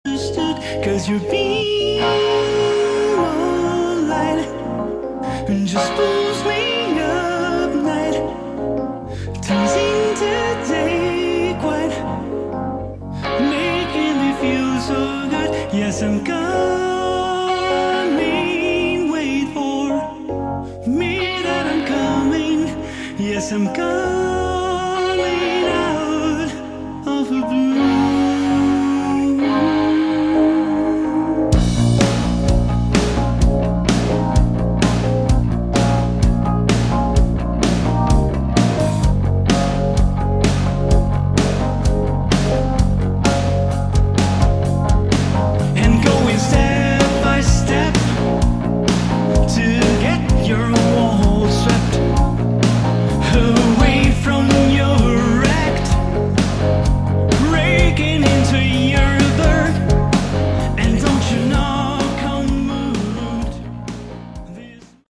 Category: AOR / Melodic Rock
vocals
guitars
keyboards
bass
drums